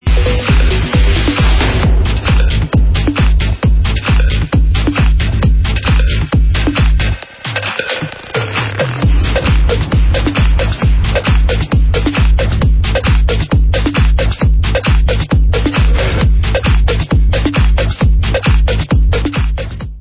sledovat novinky v oddělení Dance/Trance